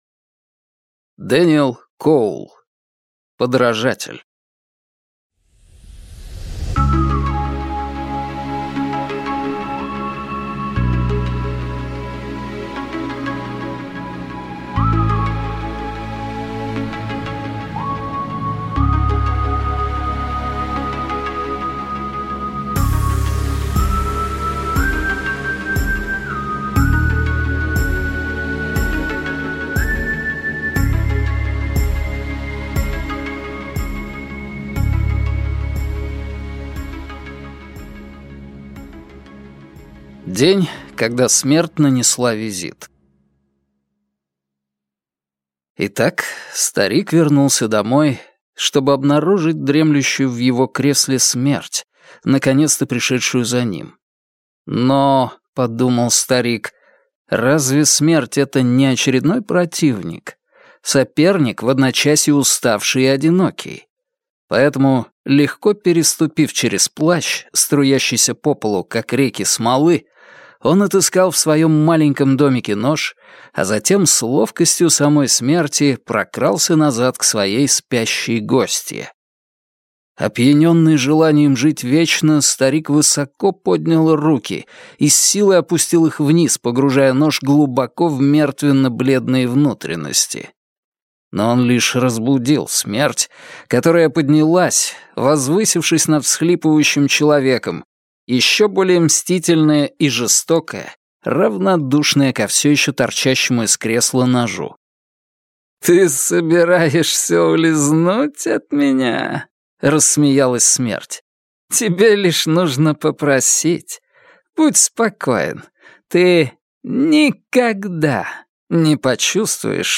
Аудиокнига Подражатель | Библиотека аудиокниг